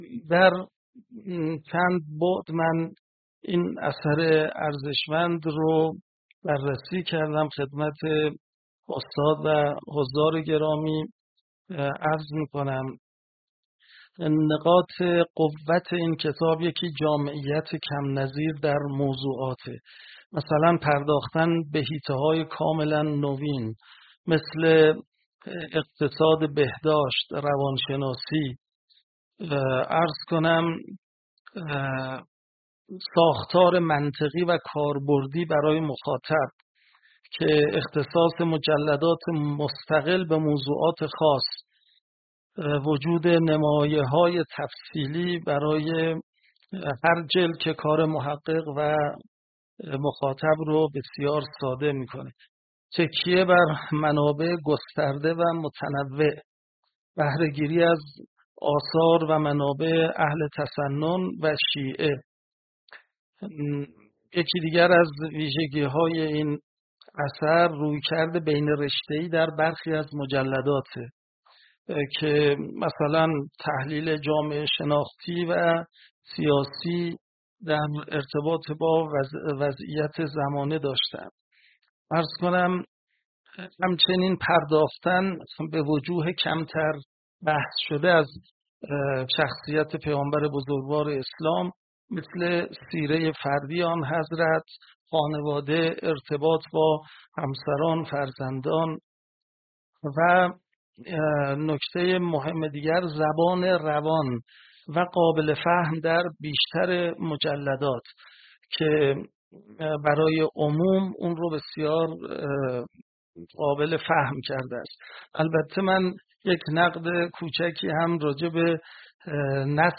گفت‌وگویی تعاملی و پژوهشی